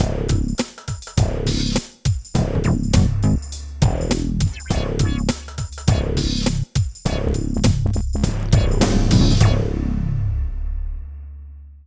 84 LOOP   -R.wav